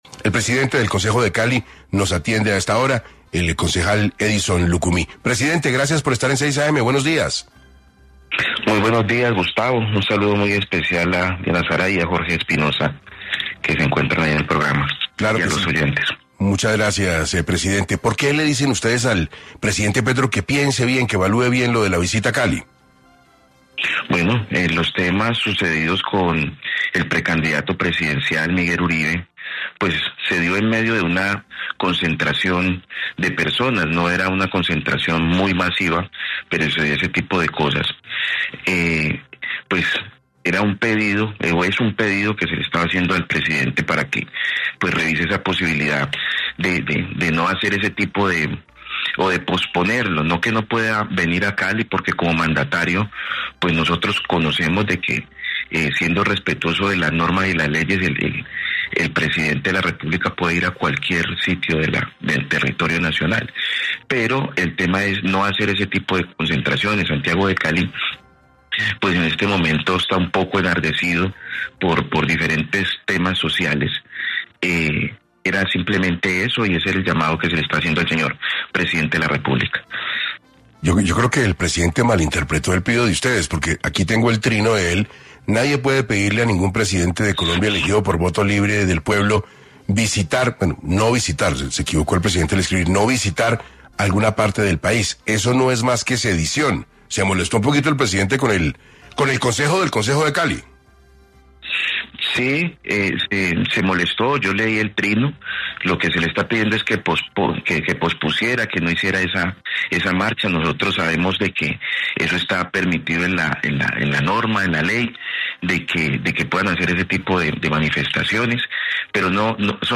Edison Lucumí, Presidente del Concejo de Cali aseguró en 6AM que a pesar de las advertencias, Gustavo Petro insiste en participar en las movilizaciones en Cali programadas para este miércoles.